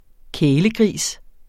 Udtale [ -ˌgʁiˀs ]